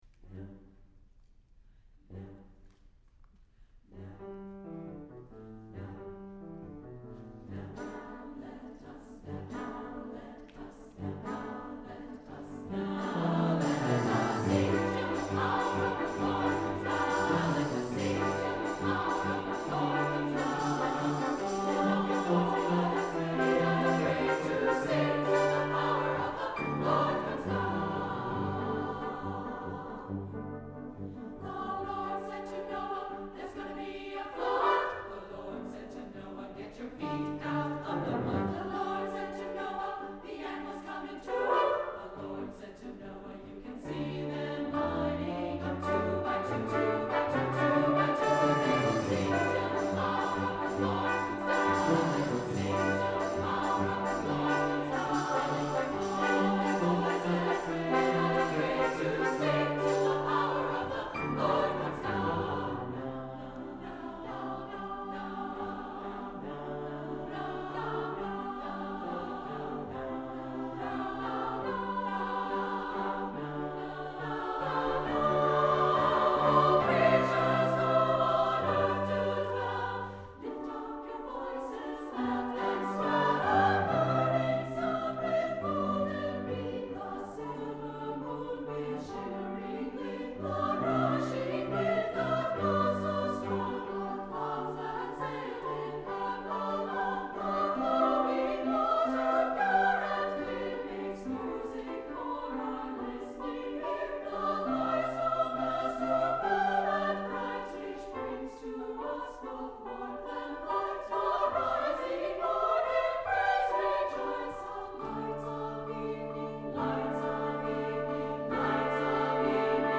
SSA, brass, percussion, and piano